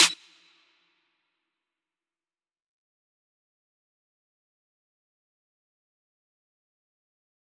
Snares
DMV3_Snare 8.wav